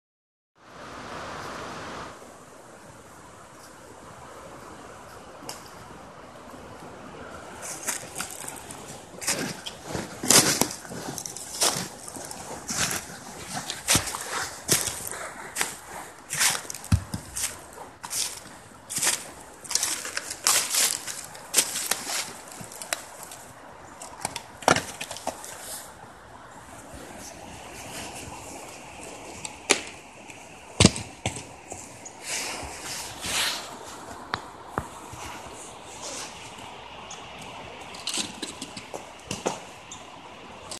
walk-in-the-woods.mp3